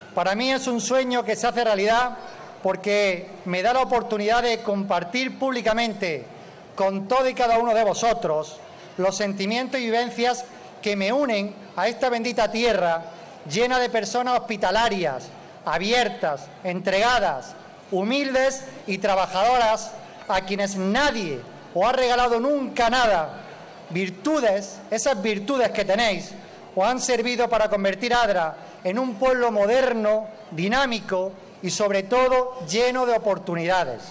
La ciudad de Adra ha dado inicio a sus esperadas Fiestas en honor a la Virgen del Mar y San Nicolás de Tolentino con un emotivo pregón a cargo de Javier Aureliano García, presidente de la Diputación de Almería y “abderitano de corazón”, acompañado por el alcalde, Manuel Cortés.
05-09_adra_fiestas_presidente_pregon.mp3